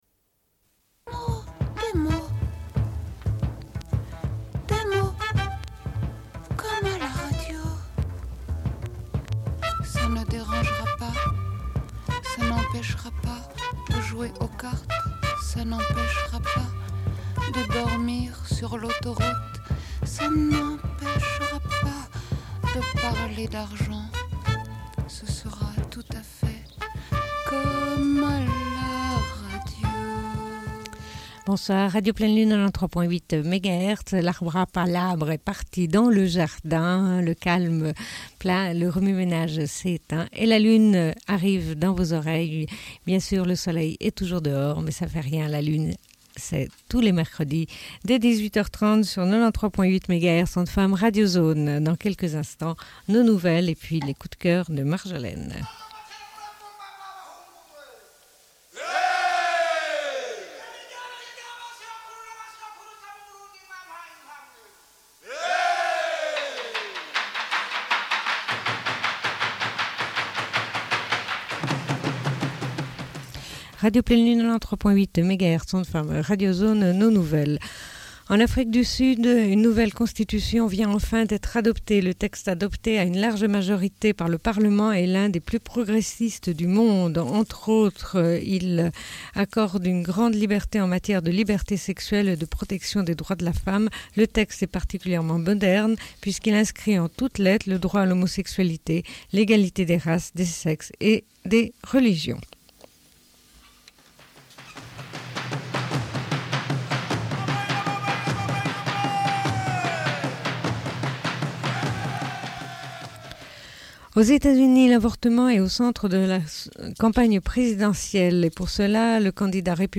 Bulletin d'information de Radio Pleine Lune du 15.05.1996 - Archives contestataires
Une cassette audio, face B